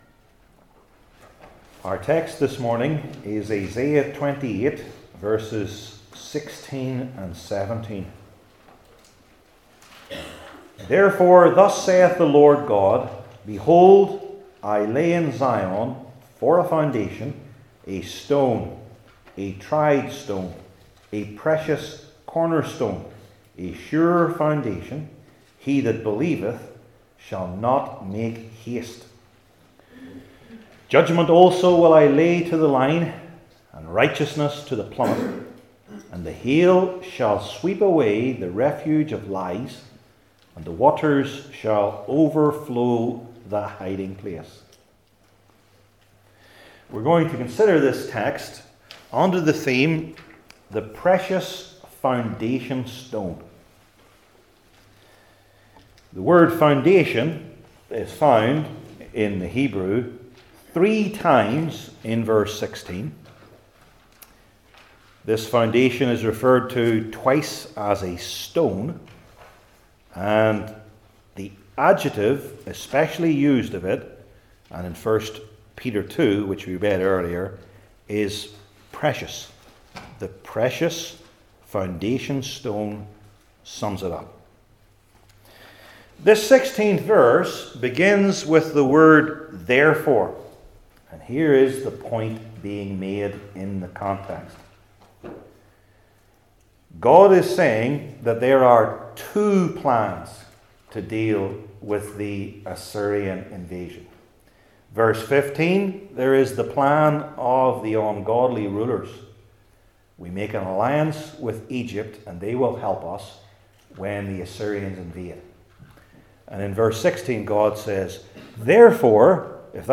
Old Testament Sermon Series I. The Rich Meaning II.